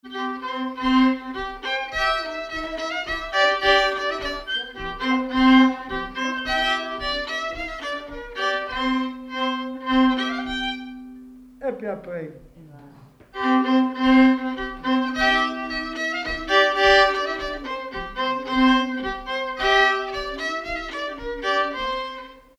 Villard-sur-Doron
circonstance : bal, dancerie ; gestuel : danse ;
Pièce musicale inédite